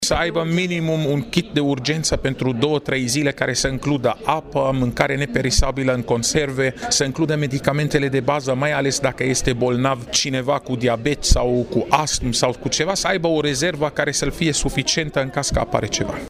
Secretarul de stat in Ministerul Afacerilor Interne, doctorul Raed Arafat, a declarat ca este bine ca oamenii sa aiba pregatita acasa si o trusa de urgenta: